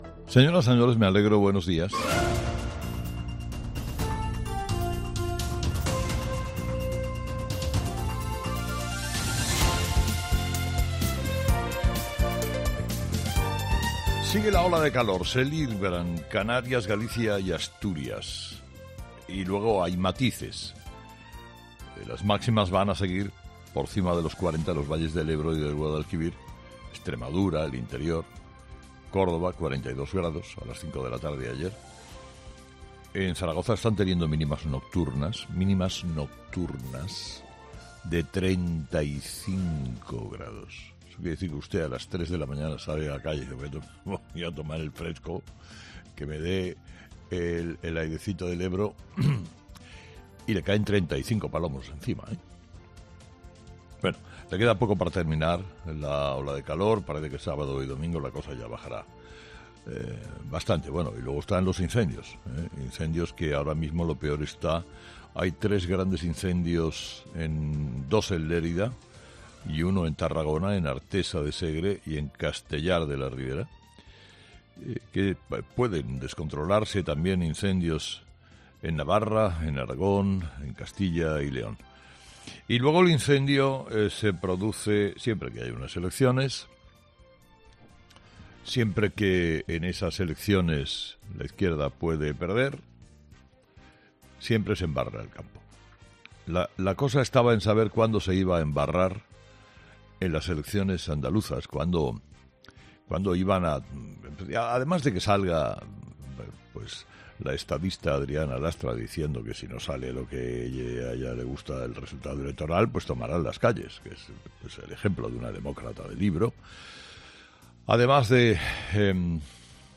Carlos Herrera analiza los principales titulares que marcarán la actualidad de este viernes 17 de junio